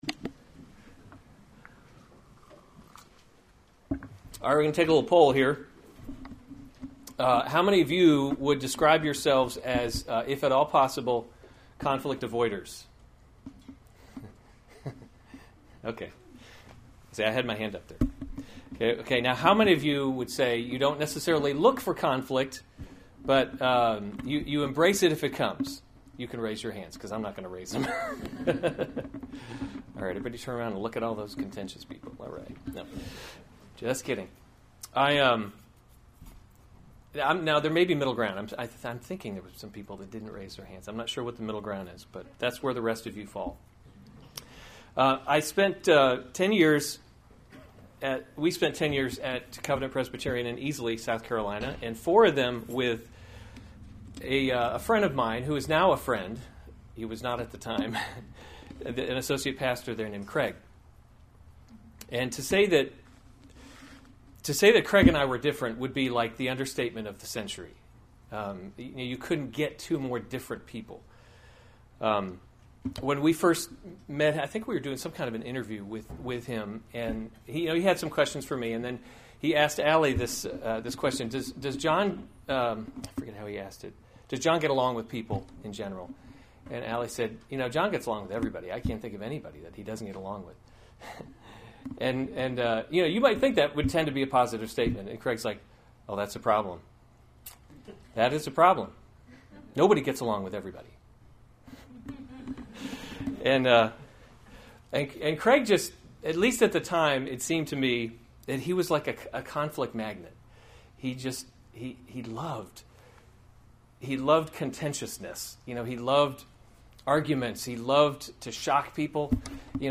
June 25, 2016 2 Thessalonians – The Christian Hope series Weekly Sunday Service Save/Download this sermon 2 Thessalonians 3:13-18 Other sermons from 2 Thessalonians 13 As for you, brothers, do not […]